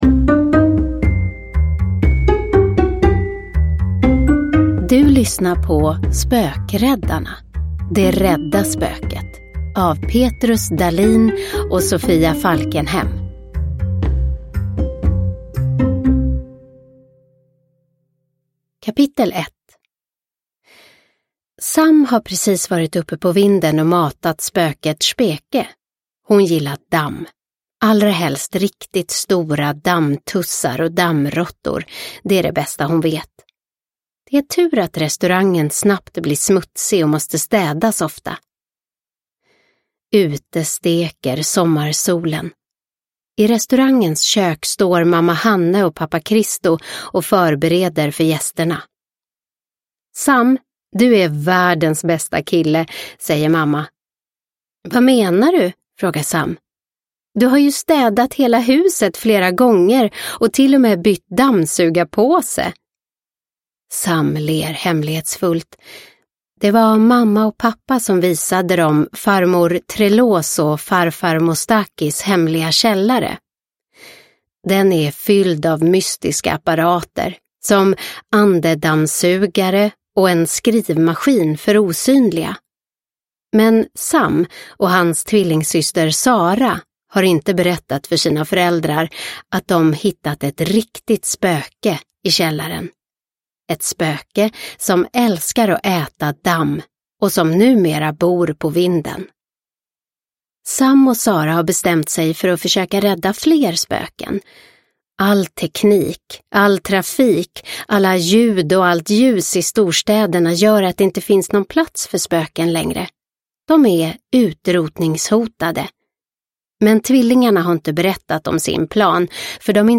Det rädda spöket – Ljudbok – Laddas ner